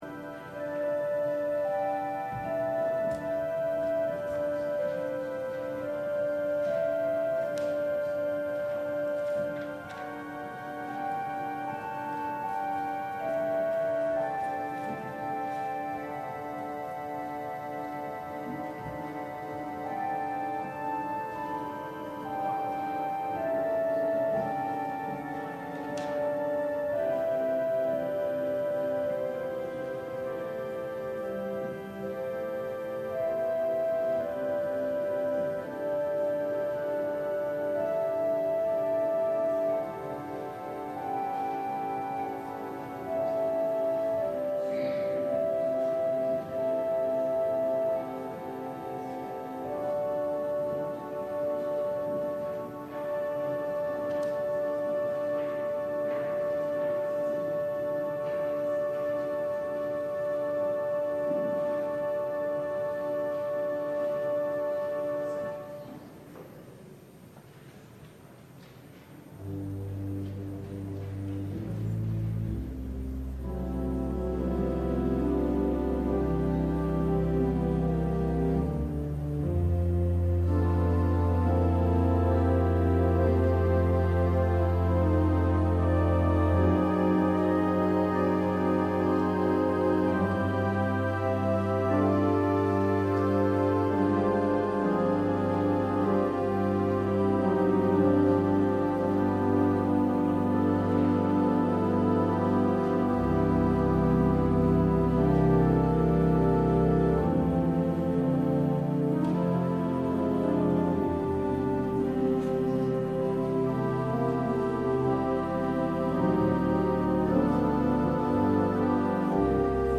LIVE Evening Worship Service - Do Not Let Your Hearts Be Troubled
Congregational singing—of both traditional hymns and newer ones—is typically supported by our pipe organ.